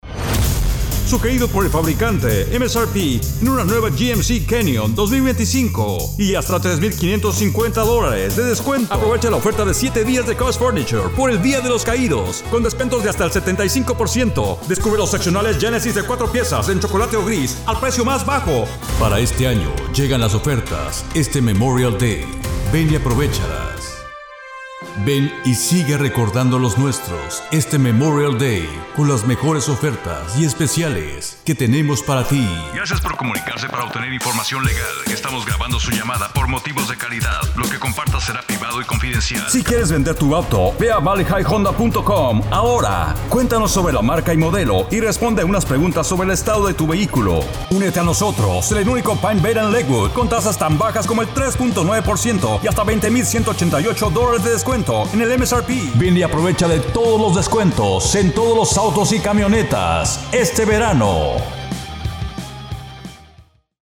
Male Voice Over Talent, Artists & Actors
Adult (30-50)